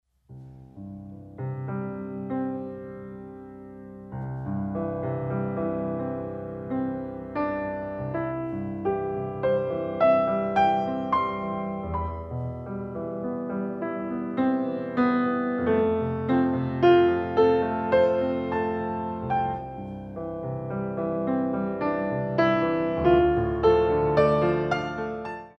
Adage